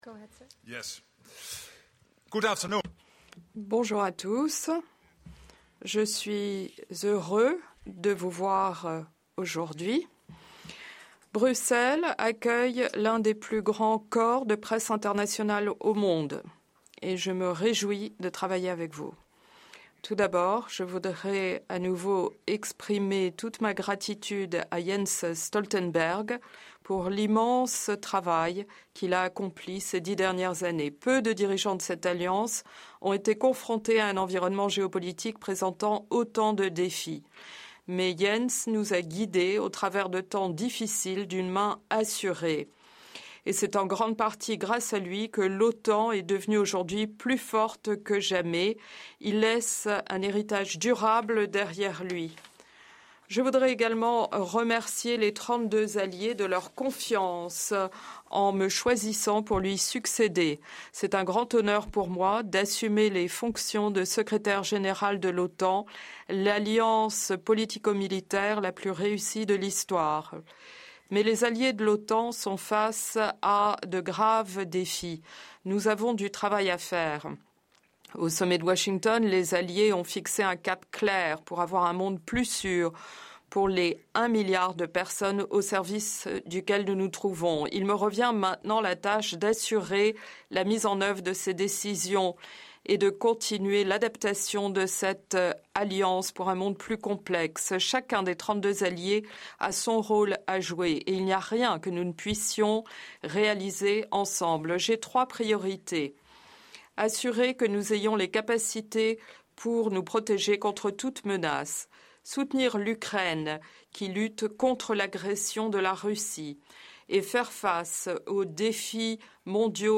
Press conference
by incoming NATO Secretary General Mark Rutte